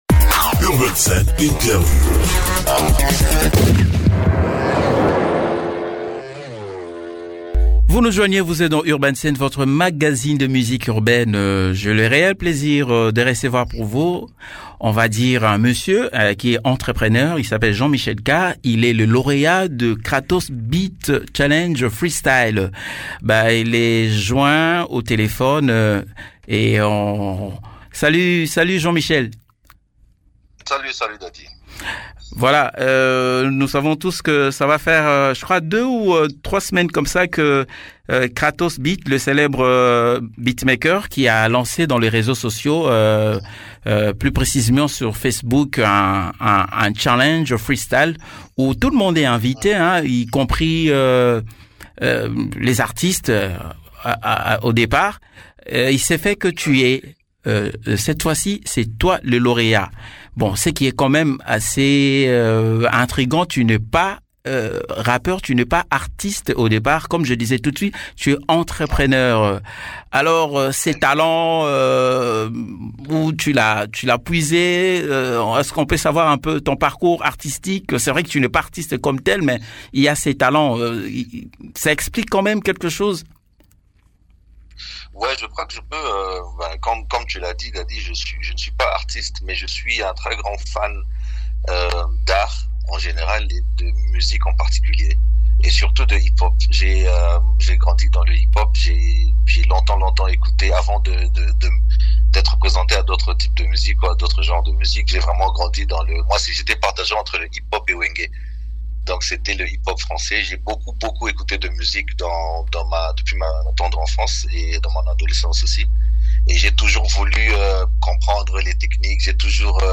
Interviuw